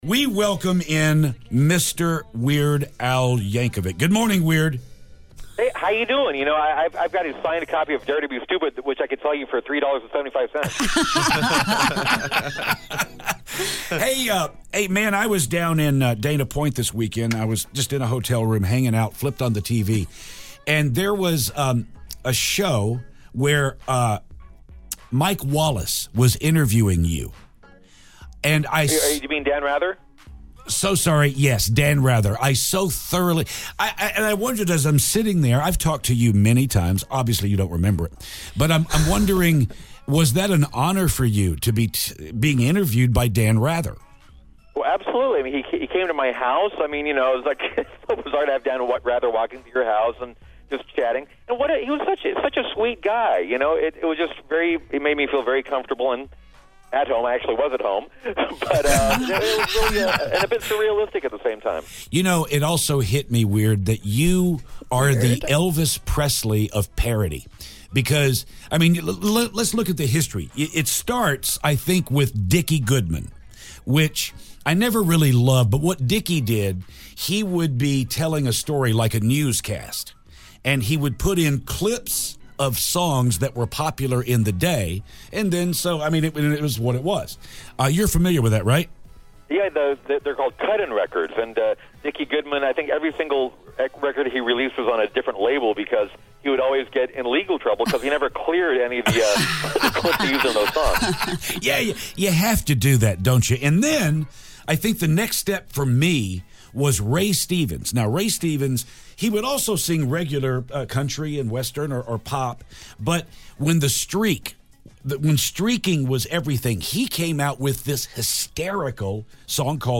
We speak to the Elvis of parody, Weird Al Yankovic.